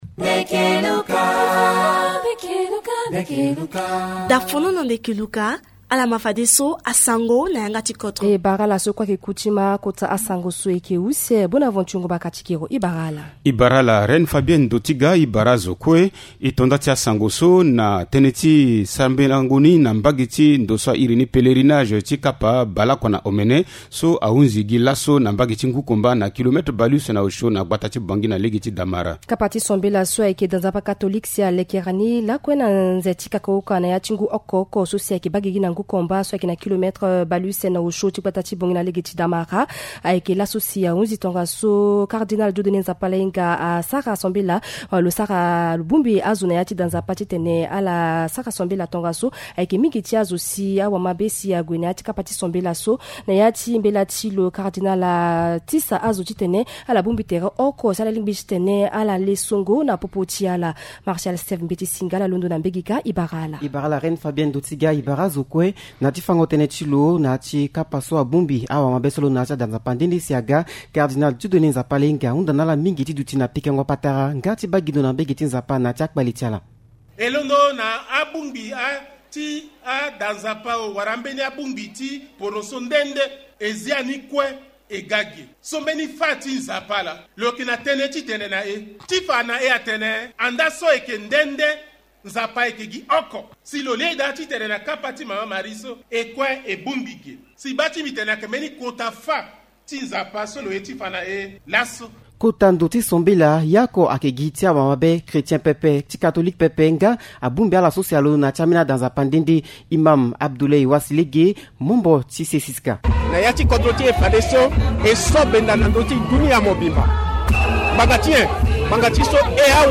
Journal en sango